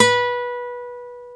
NYLON B 3.wav